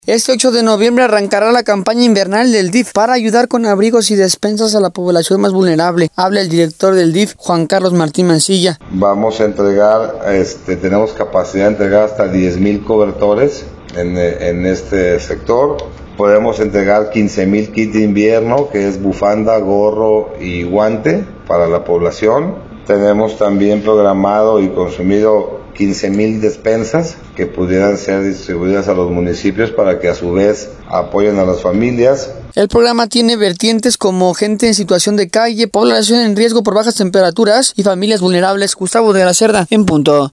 Este 8 de noviembre arrancará la campaña invernal del DIF para ayudar con abrigos y despensas a la población más vulnerable, habla el director del DIF, Juan Carlos Martín Mancilla: